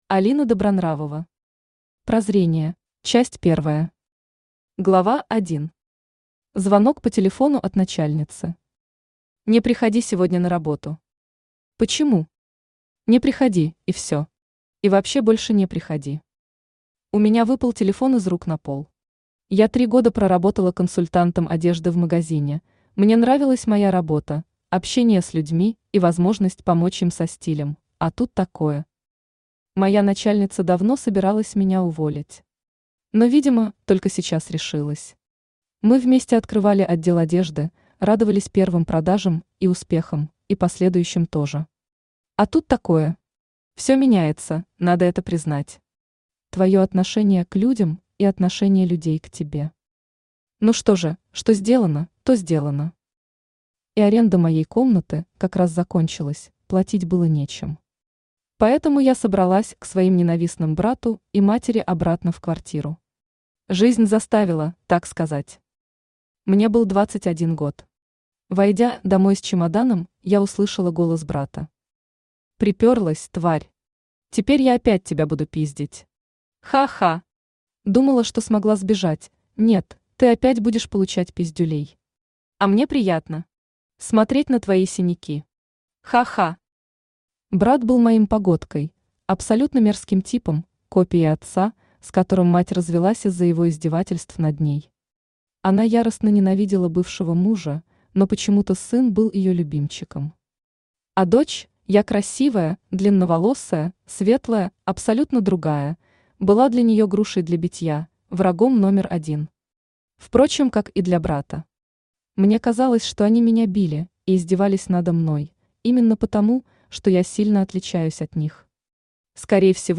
Аудиокнига Прозрение | Библиотека аудиокниг
Aудиокнига Прозрение Автор Алина Добронравова Читает аудиокнигу Авточтец ЛитРес.